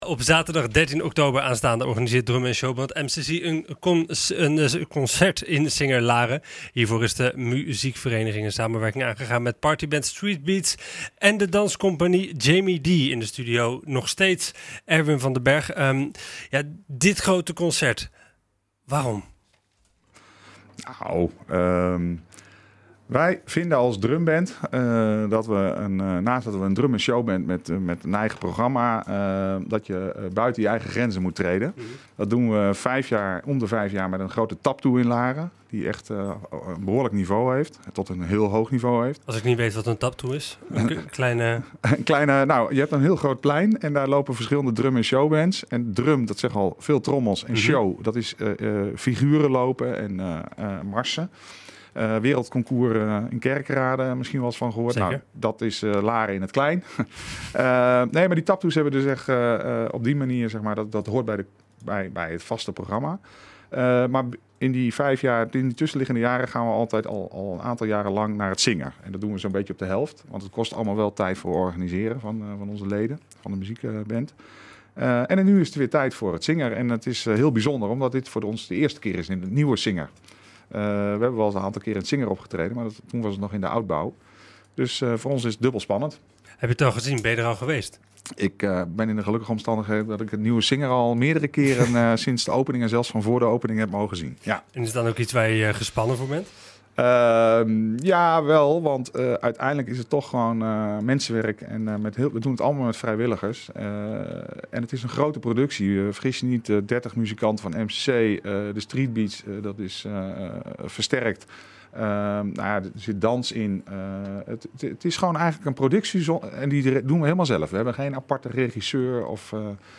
In de studio